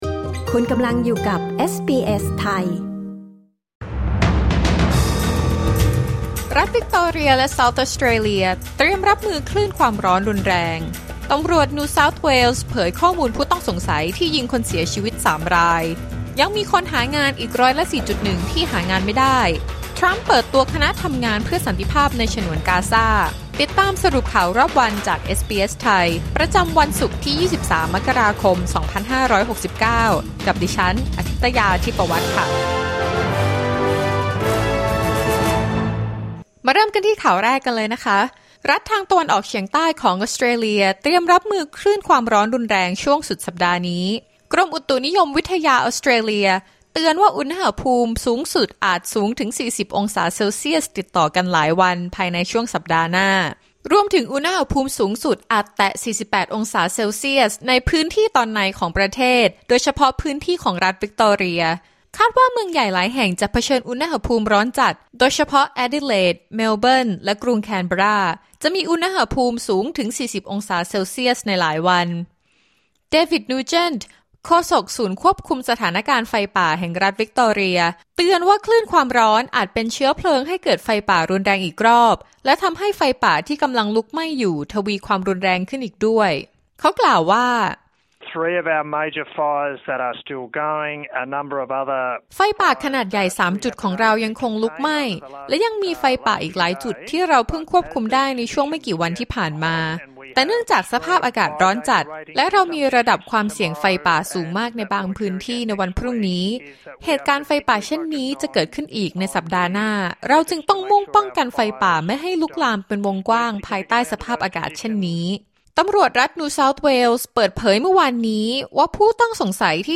สรุปข่าวรอบวัน 23 มกราคม 2569